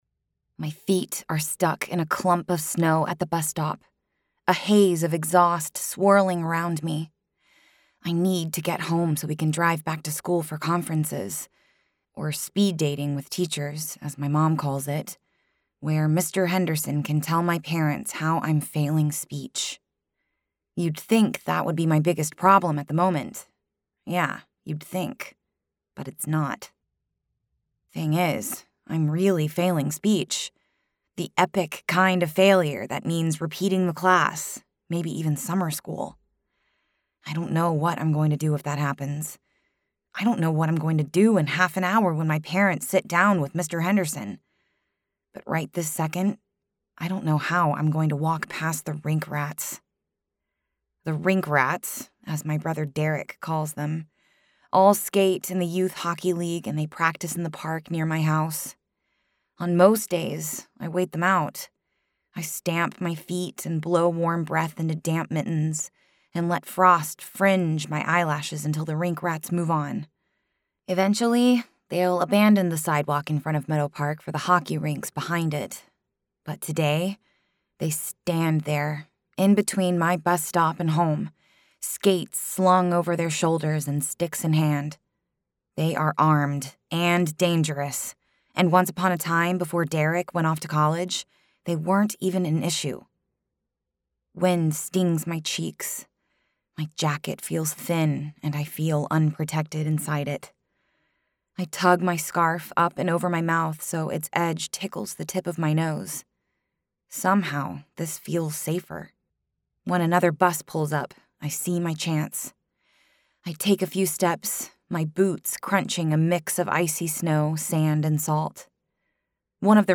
If you enjoy audio books and would like to listen to either or both, send me a note via the contact form.